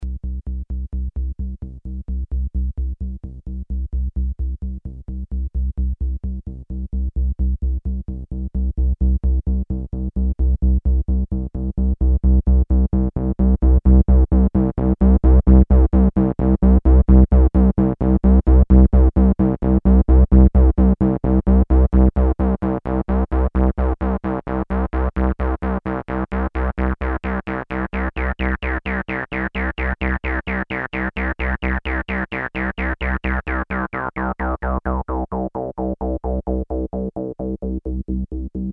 TEST NOTE: The Tb 303 is on the LEFT channel and always start first.
Test 3 : VS resonance
vs-resonance.mp3